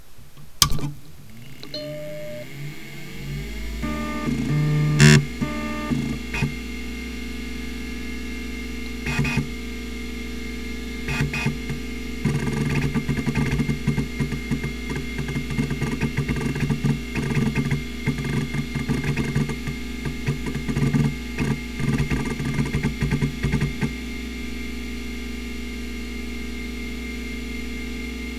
mac_se_startup.wav